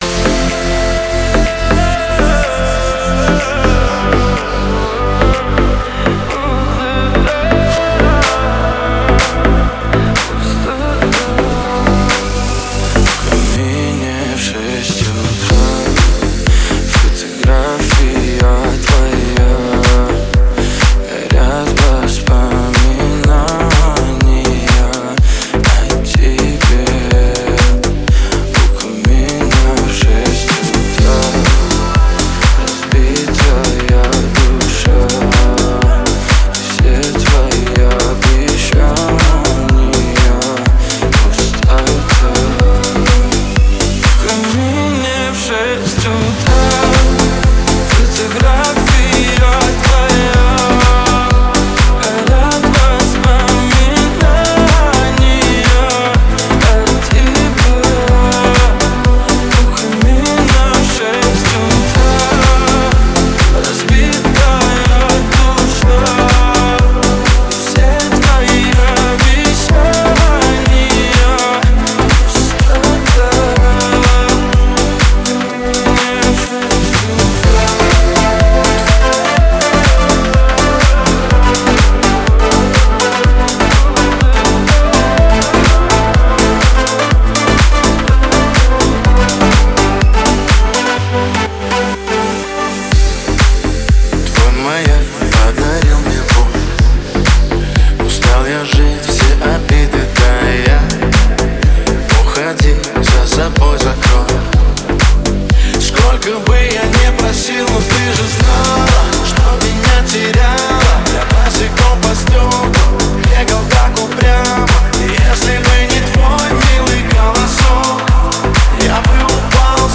Эмоциональные вокалы